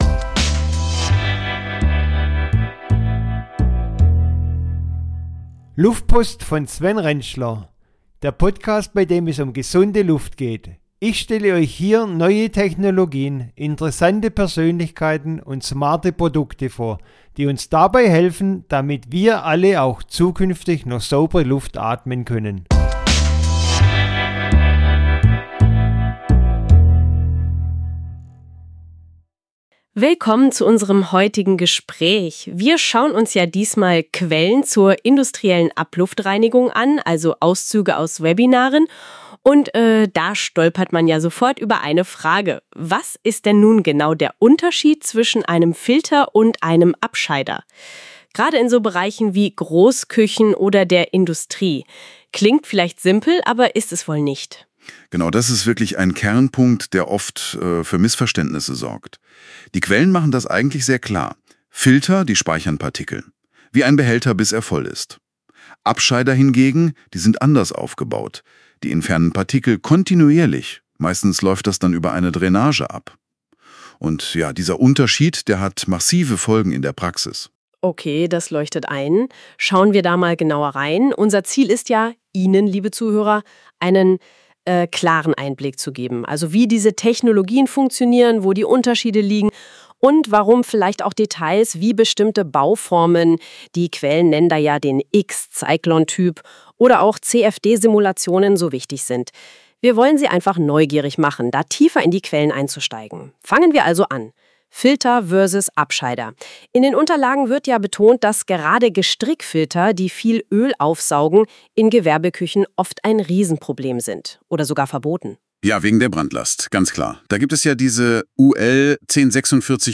Ein Talk mit und von der Google KI